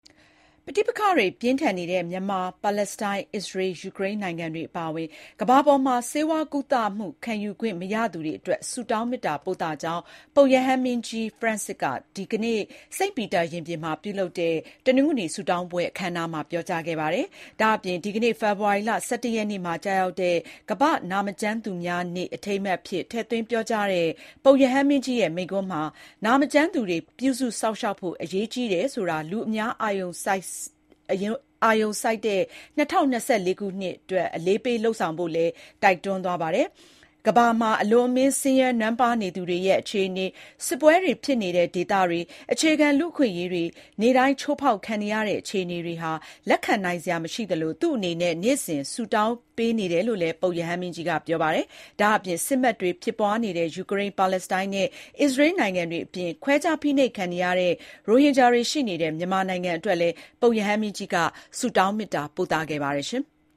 ပဋိပက္ခတွေ ပြင်းထန်နေတဲ့ မြန်မာ၊ ပါလက်စတိုင်း၊ အစ္စရေး၊ ယူကရိန်းနိုင်ငံတွေအပါအဝင် ကမ္ဘာပေါ်မှာ ဆေးဝါးကုသမှု ခံယူခွင့်မရသူတွေအတွက် ဆုတောင်းမေတ္တာပို့သကြောင်း ပုပ်ရဟန်းမင်းကြီး ဖရန်စစ်က ဒီကနေ့ စိန့်ပီတာရင်ပြင်မှာ ပြုလုပ်တဲ့ တနင်္ဂနွေဆုတောင်းပွဲ အခမ်းအနားမှာ ပြောကြားခဲ့ပါတယ်။